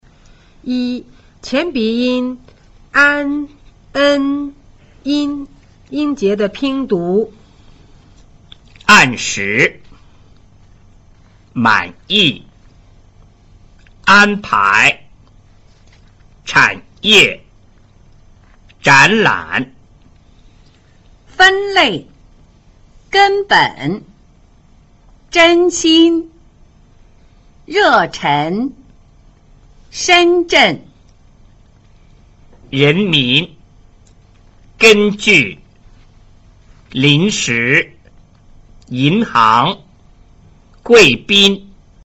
1 前鼻音 an en in音節的拼讀